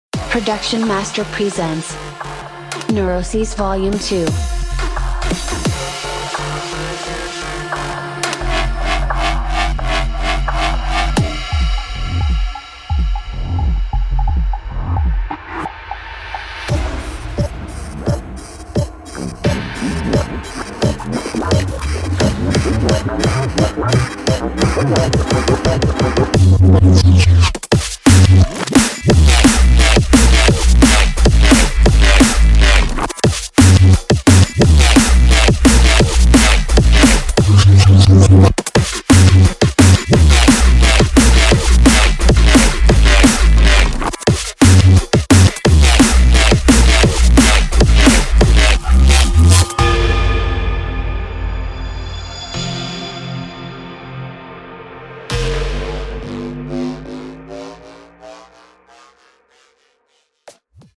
Drum And Bass
14 mood setting atmosphere loops
36 dark arp & melody loops
220 heavy hitting bass shots & loops
36 monstrous drum loops
142 handcrafted drum one shots and percussions